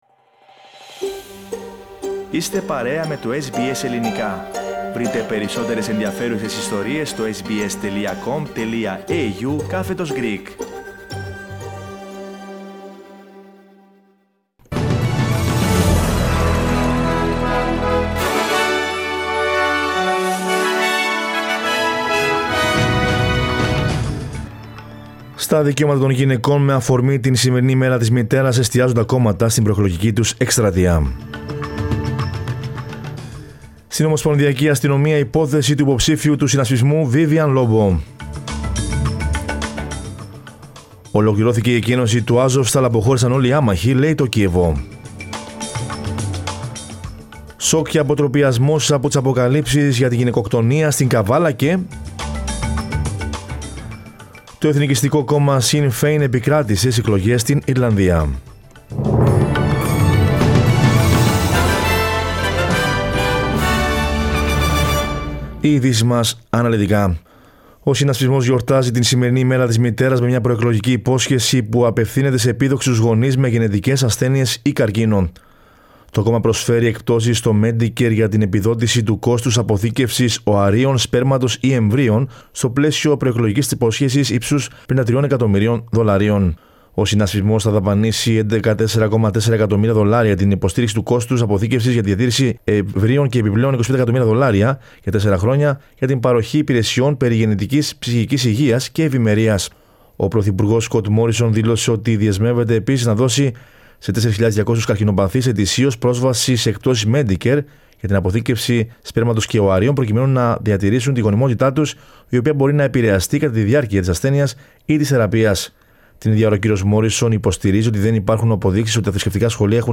Δελτίο Ειδήσεων Κυριακής 08.05.22